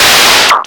RADIOFX  9-L.wav